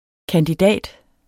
Udtale [ kandiˈdæˀd ]